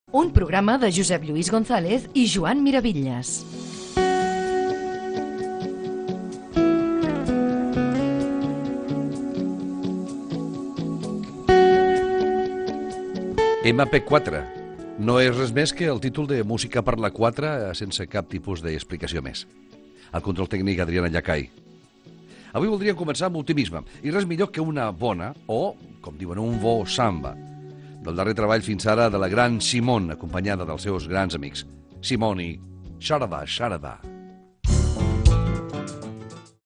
Careta del programa, presentació i tema musical
Musical